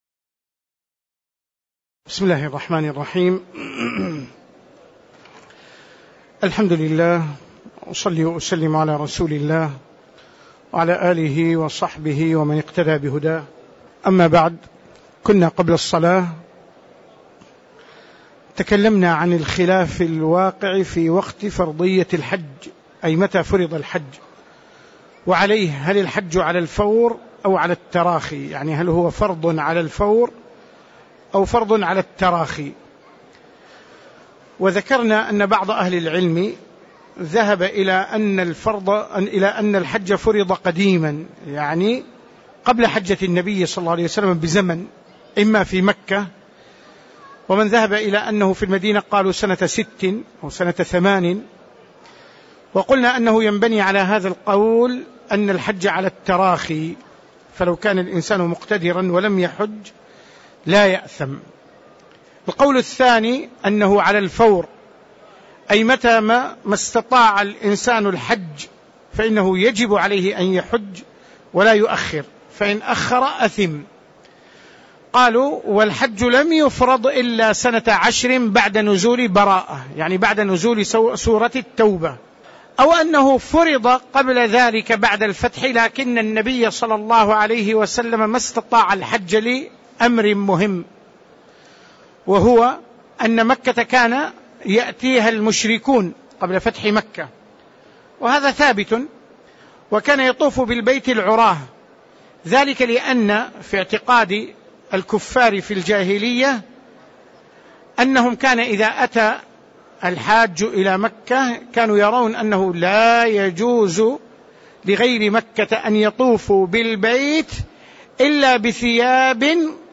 تاريخ النشر ٥ ذو القعدة ١٤٣٧ هـ المكان: المسجد النبوي الشيخ